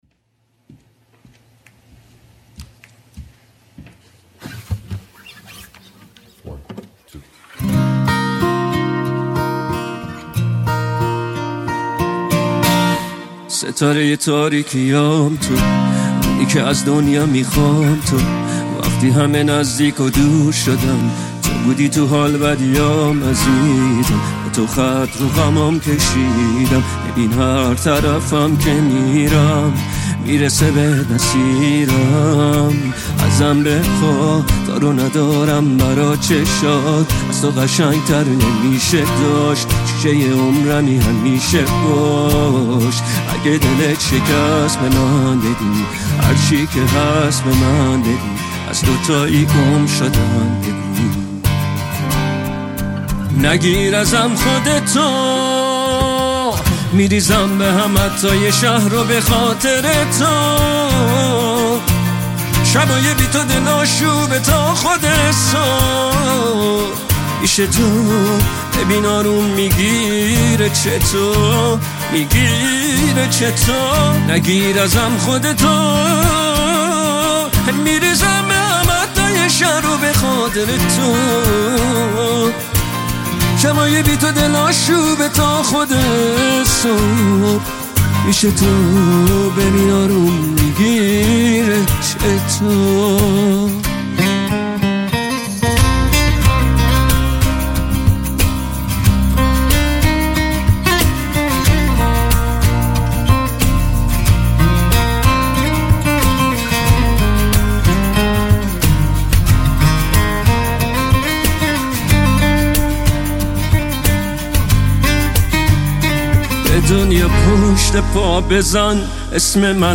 acoustic Version
پاپ عاشقانه غمگین